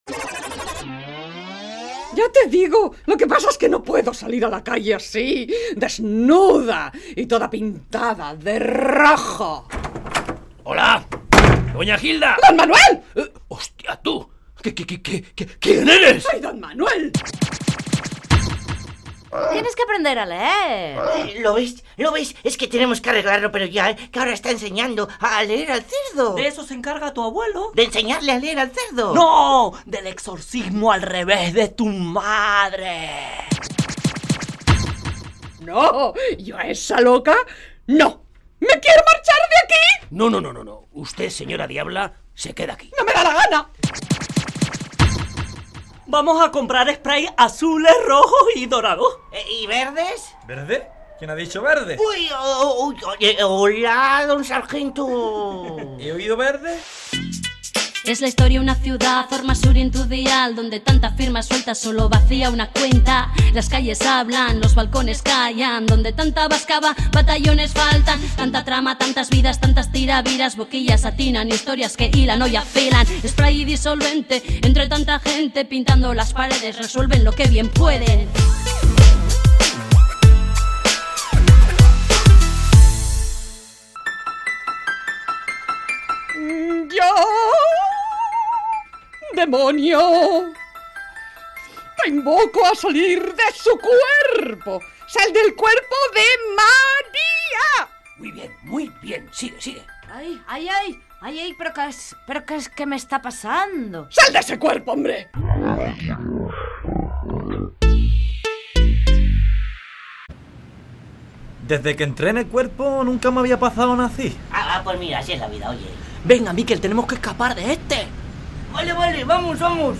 Entrega número 34 de la Radio-Ficción “Spray & Disolvente”